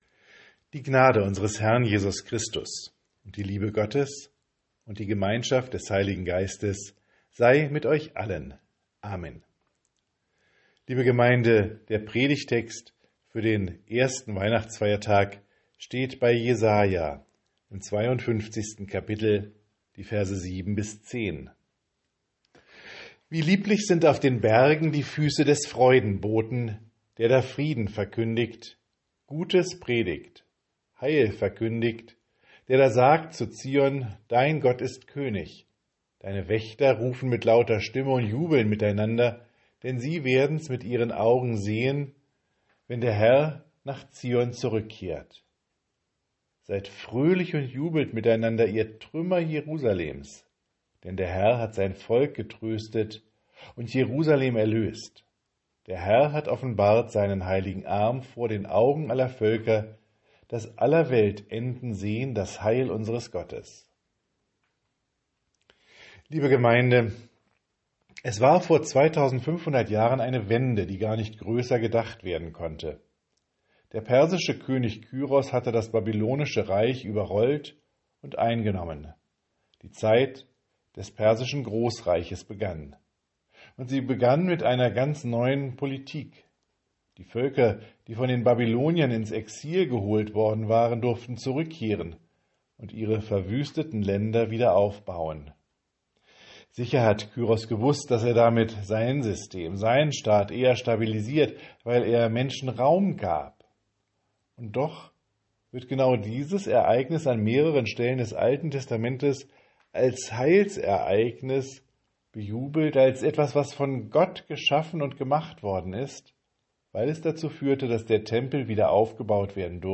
Predigt für Zu Hause